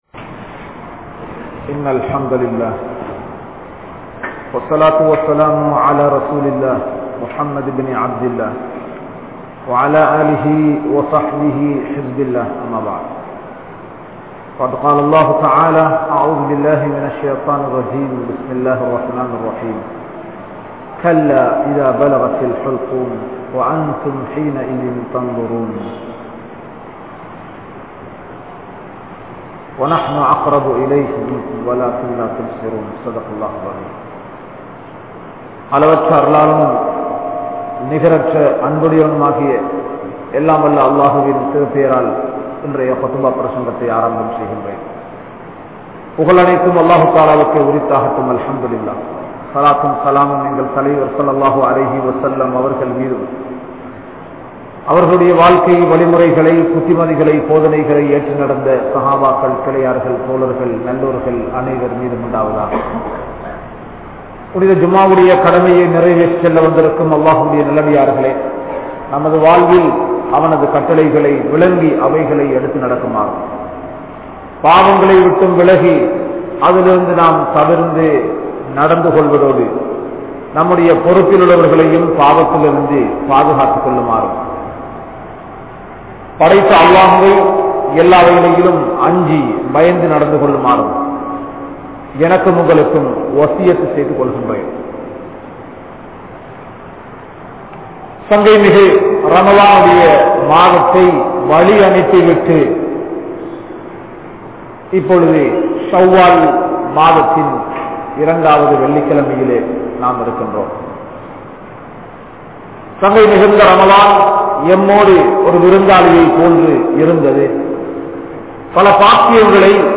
Sakraath | Audio Bayans | All Ceylon Muslim Youth Community | Addalaichenai
Saliheen Jumua Masjith